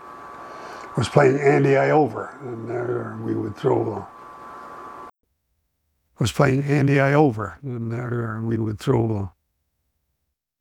Background noise problem
I’m working on editing some oral histories I’ve recorded in the homes of several neighbors. I’ve tried using noise reduction and noise gate to reduce some particularly annoying background hum but the voice ends up sounding very distorted.
Not mains hum, the mains hum plugin won’t help. 2 or 3 notch filters helps …